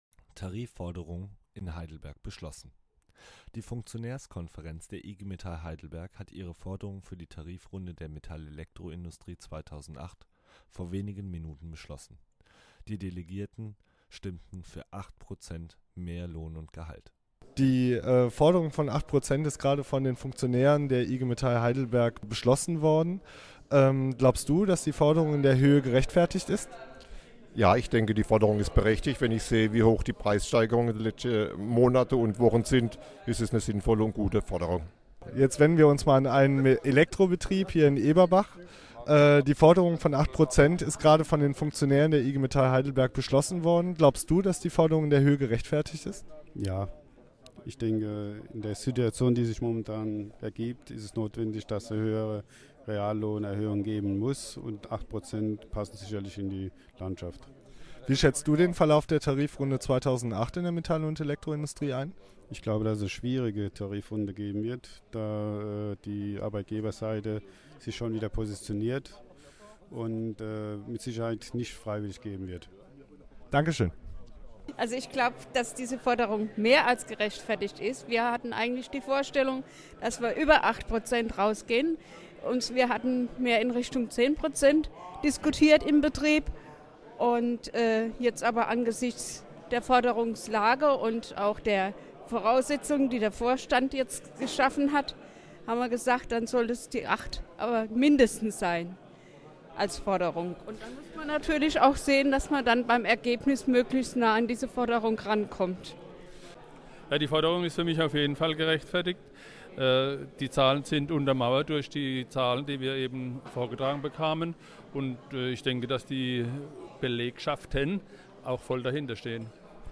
O-Töne zur Forderungshöhe